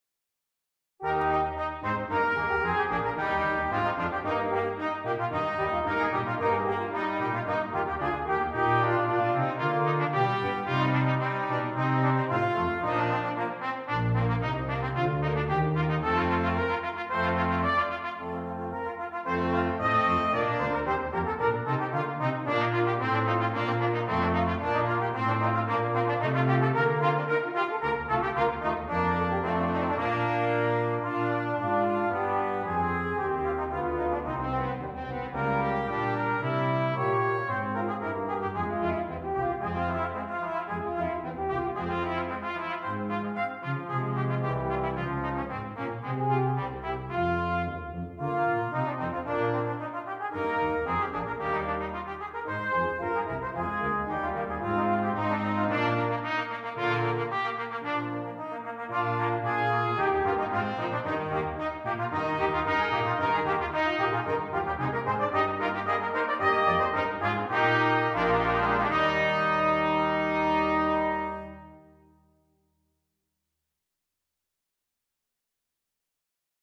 • Brass Quintet